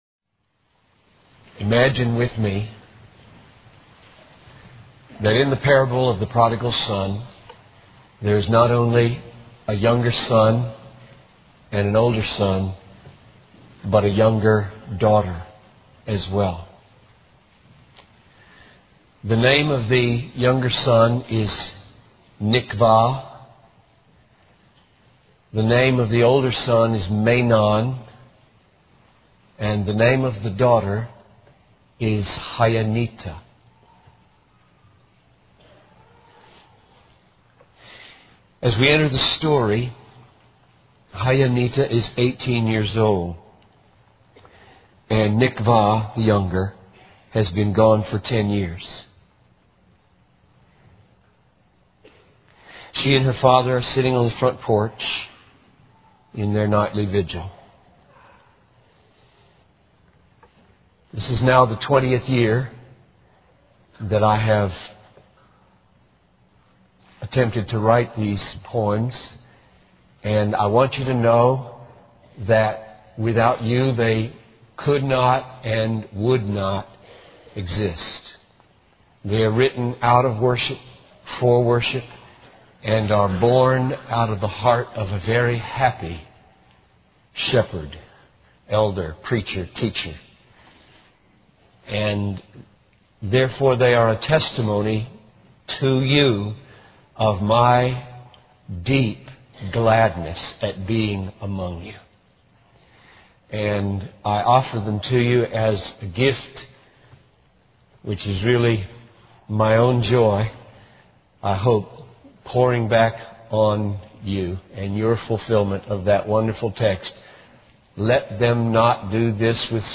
In this sermon, the preacher discusses the parable of the prodigal son from Luke 15:11-32. The sermon focuses on the reason why Jesus ate with sinners, as explained in this parable. The preacher describes the story of a young girl named Hyonita, who longs for her brother to return home.